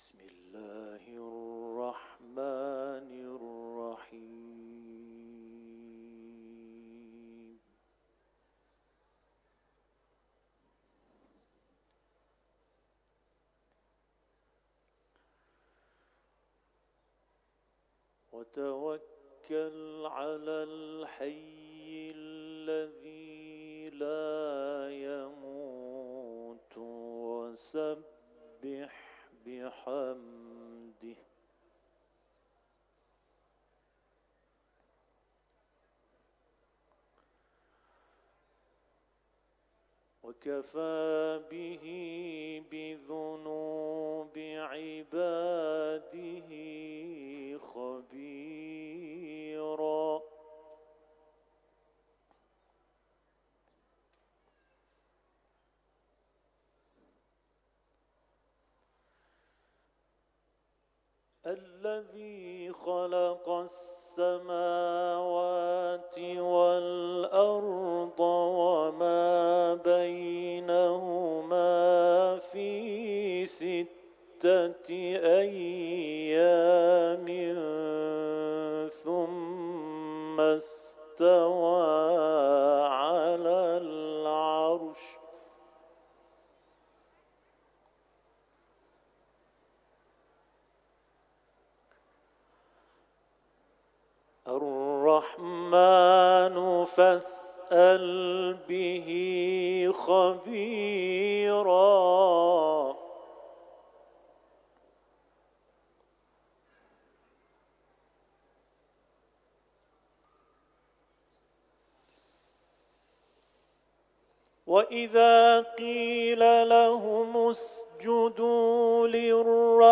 صوت تلاوت آیات ۵۸ تا ۶۷ سوره «فرقان»